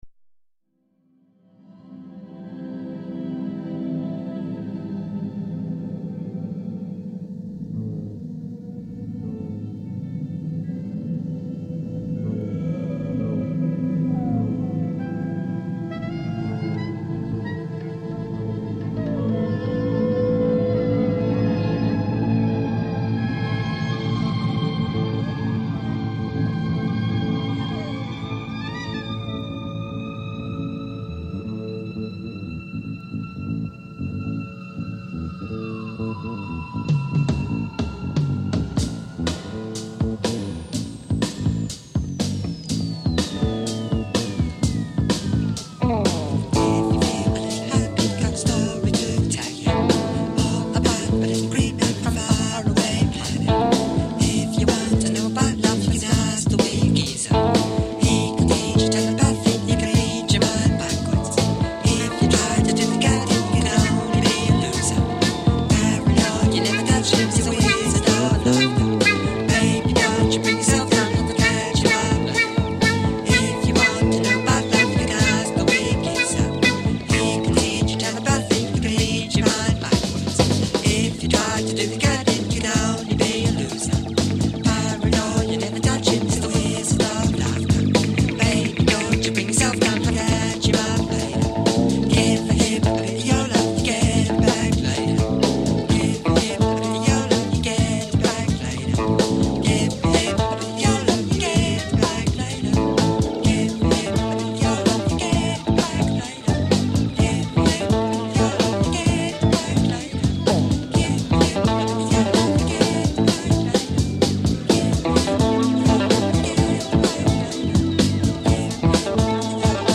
They play a lot of jazz FUSION on the surface of the sun…